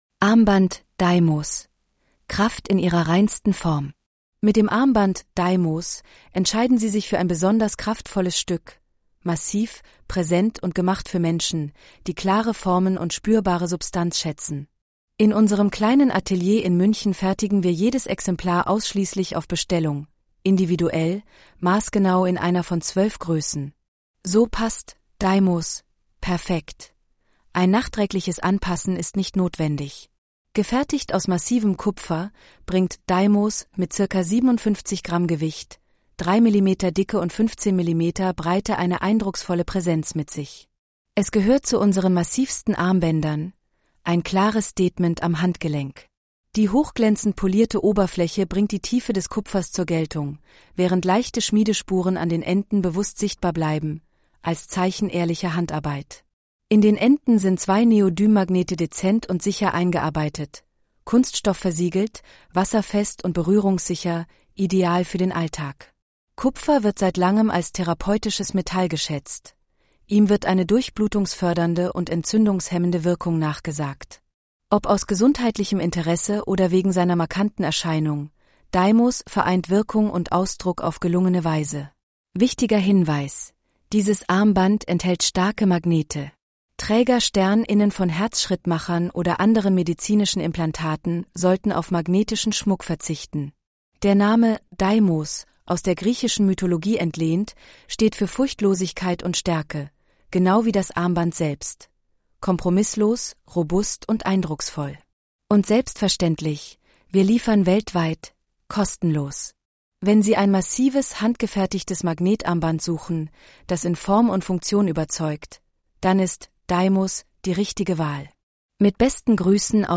Deimos-ttsreader.mp3